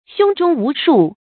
胸中無數 注音： ㄒㄩㄥ ㄓㄨㄙ ㄨˊ ㄕㄨˋ 讀音讀法： 意思解釋： 指對情況了解不清楚，心里沒有底。